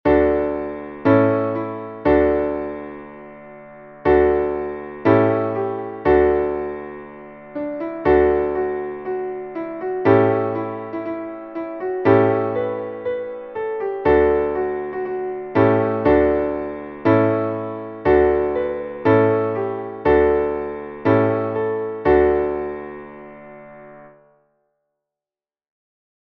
Traditionelles Bergmannslied